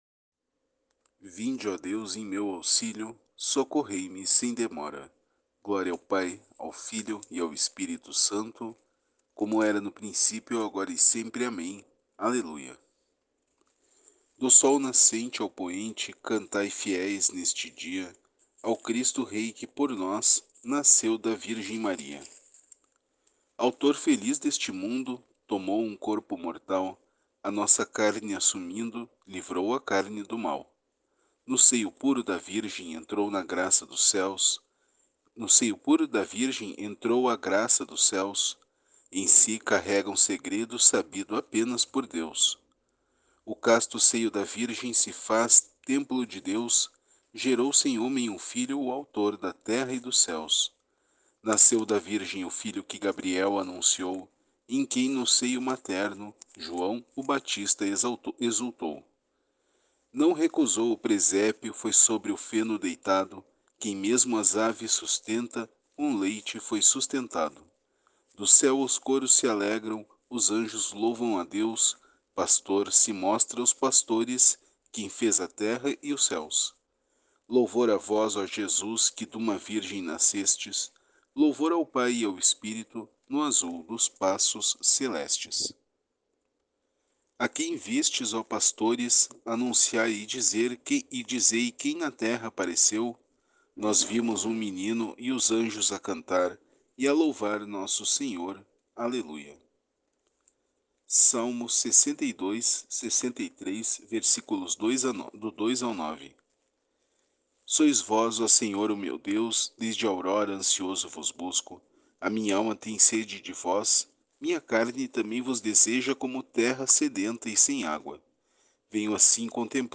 Oração no dia 25 de dezembro